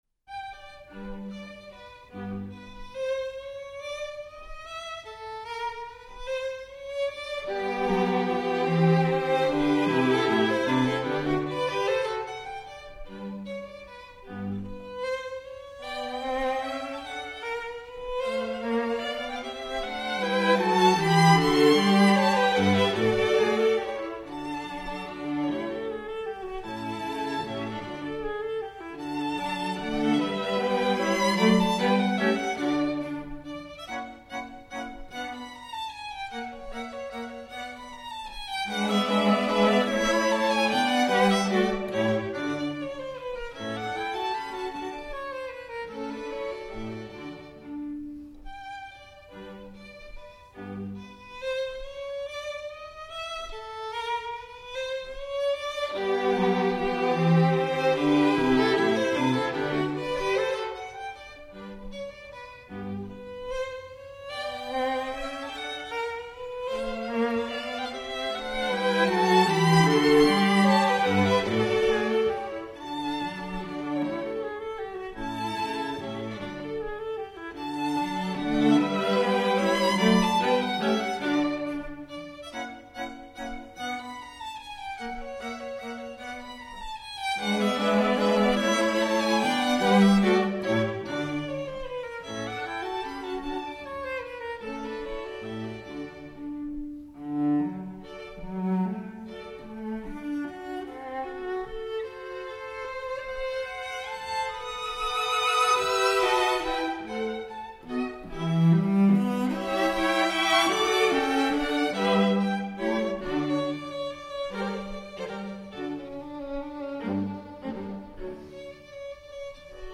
String Quartet in G major
Menuetto. Allegretto